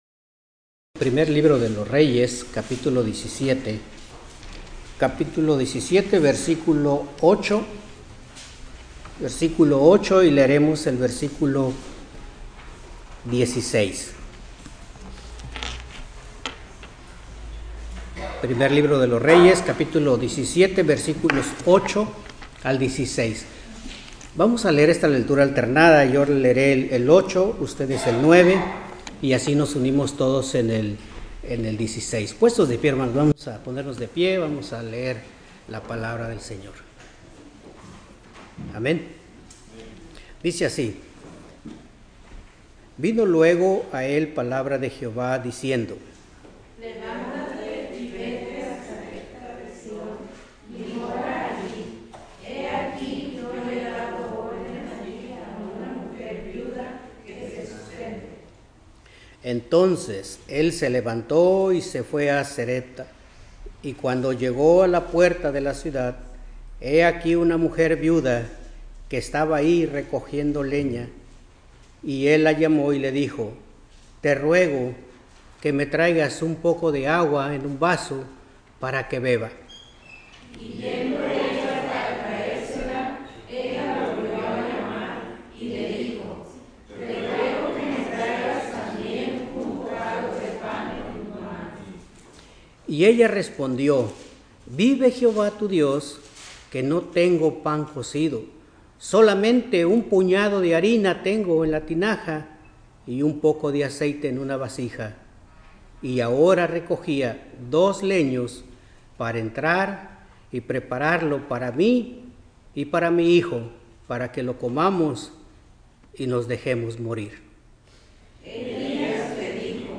Escuela Dominical